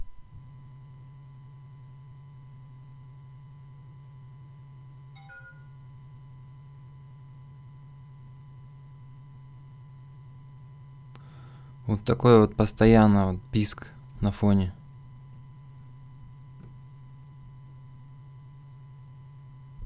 Rode NT Usb постоянный фоновый писк
Проверил - присутствует постоянный фоновый шум типа писка плюс низкочастотное "урчание".